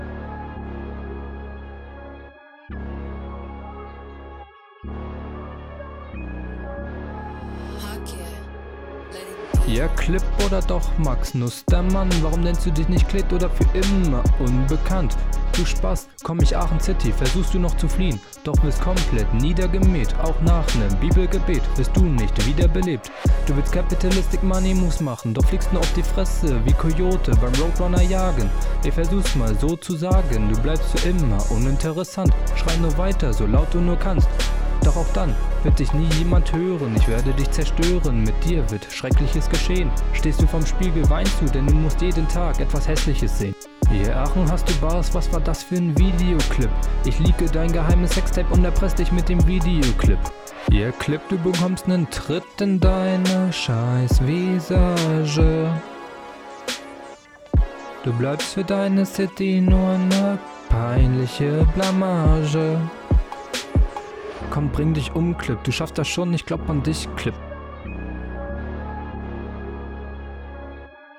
Das ist so schwach gerappt und betont, dass ich mich ehrlich frage, ob das ein …